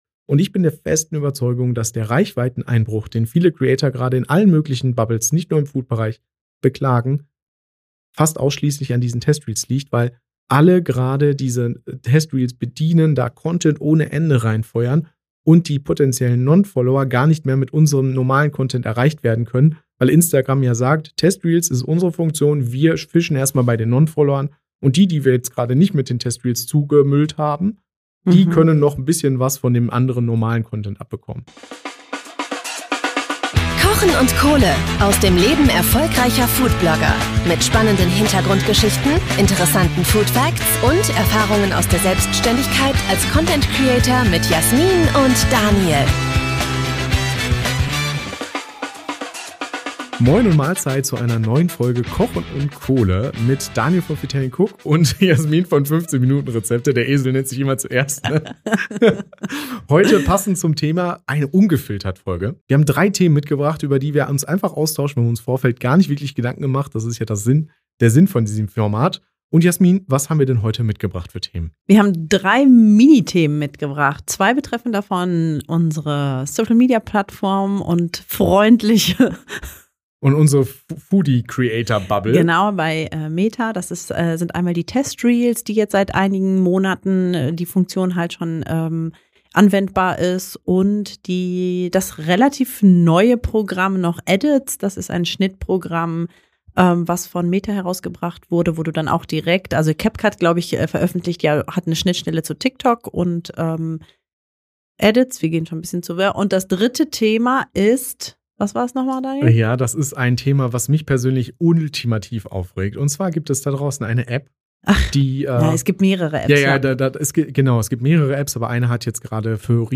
In dieser Folge wird’s ehrlich, roh und direkt. Ohne Skript, ohne Filter. Wir sprechen über unsere Gedanken zur aktuellen Entwicklung im Creator-Game, erzählen vom Druck hinter der Kamera und teilen, was uns gerade wirklich beschäftigt.